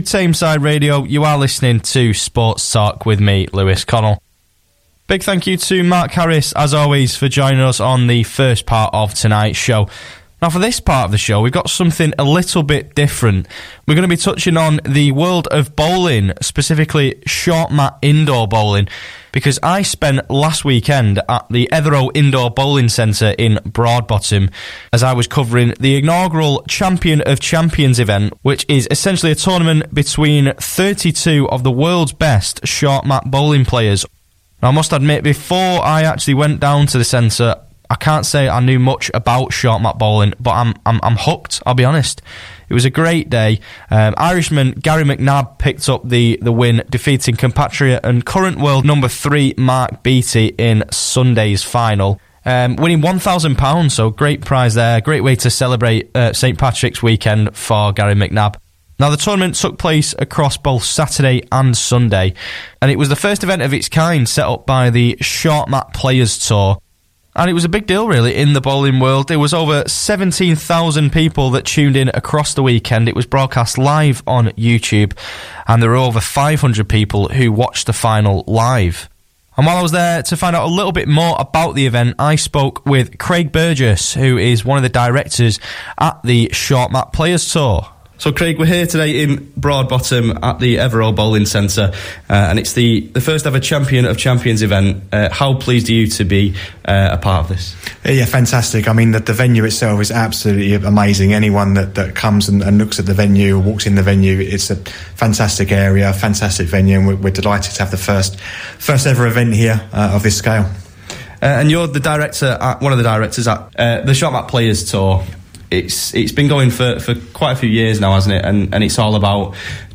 Tameside Radio Interview